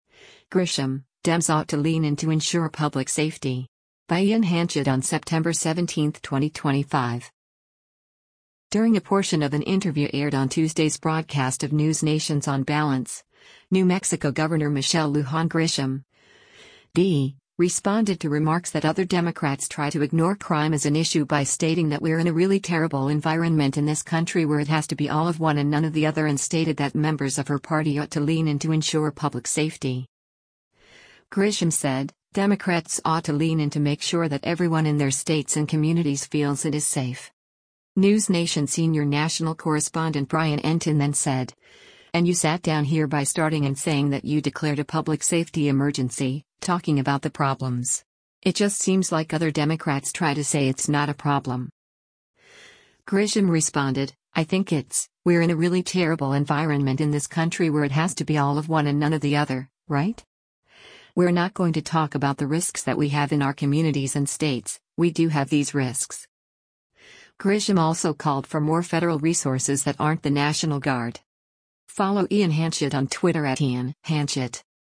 During a portion of an interview aired on Tuesday’s broadcast of NewsNation’s “On Balance,” New Mexico Gov. Michelle Lujan Grisham (D) responded to remarks that other Democrats try to ignore crime as an issue by stating that “we’re in a really terrible environment in this country where it has to be all of one and none of the other” and stated that members of her party “ought to lean in” to ensure public safety.